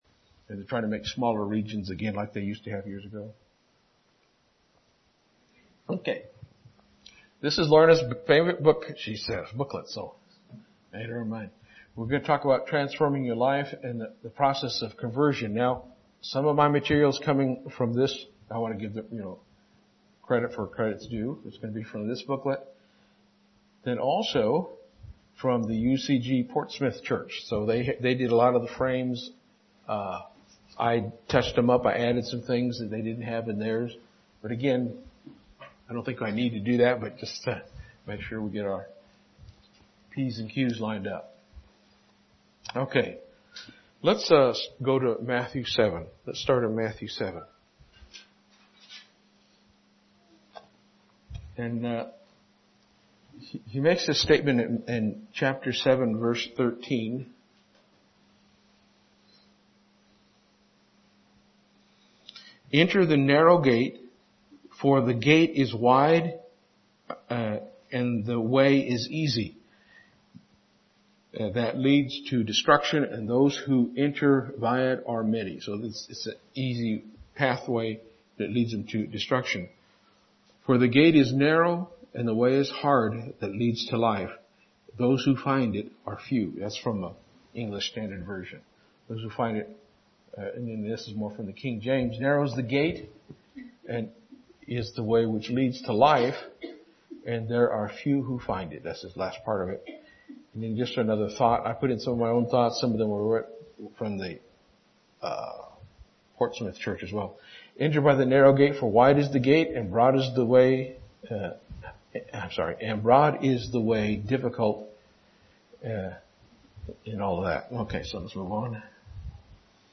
Are you fighting with sin in your life? Does it seem you are not growing? Find the answers in this sermon.